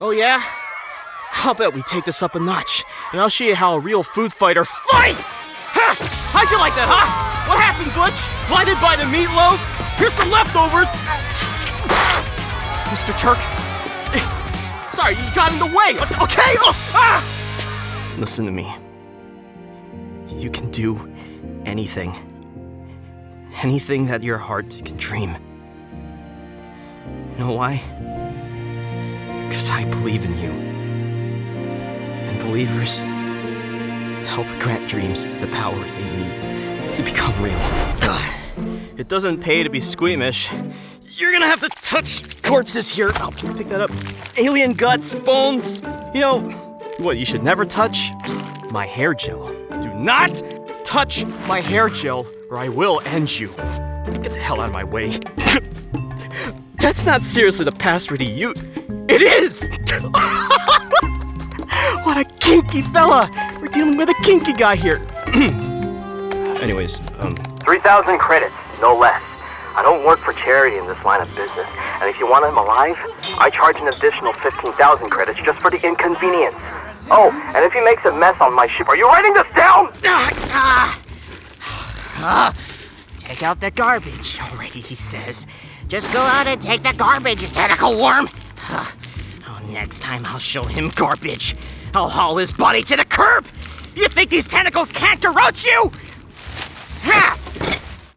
Teenager, Young Adult, Adult, Mature Adult
ANIMATION 🎬
broadcast level home studio